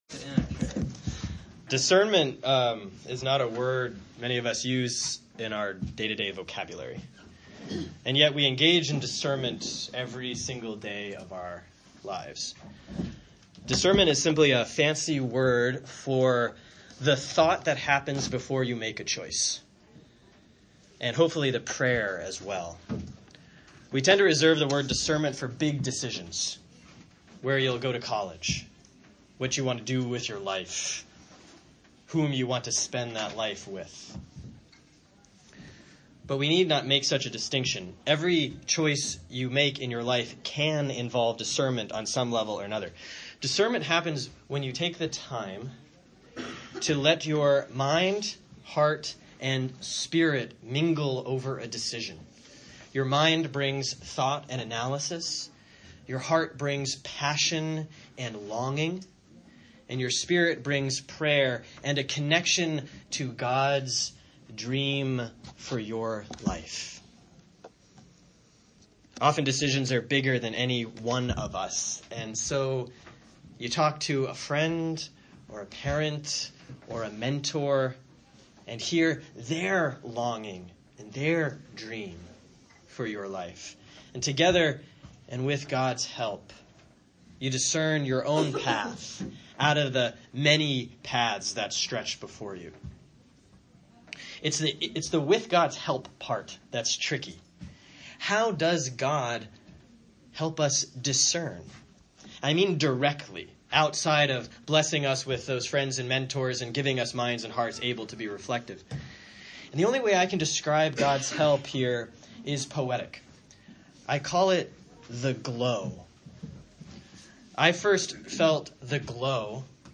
Given at a Youth Retreat the Last Weekend of March 2017